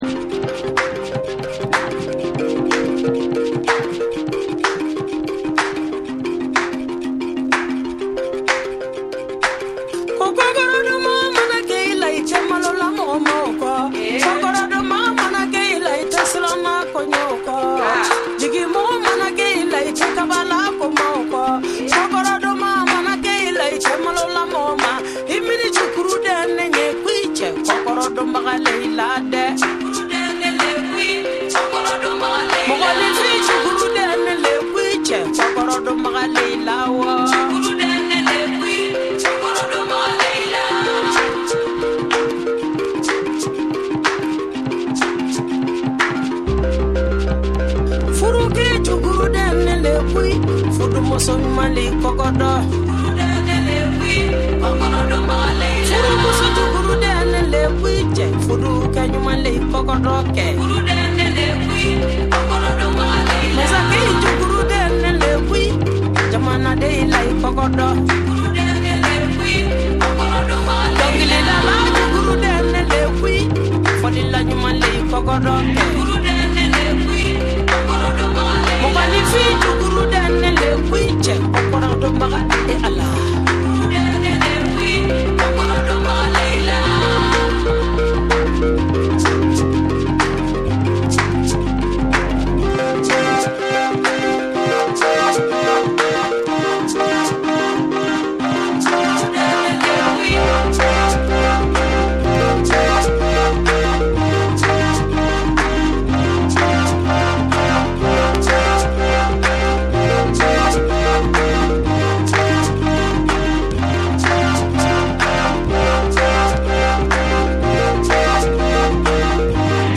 TECHNO & HOUSE / ORGANIC GROOVE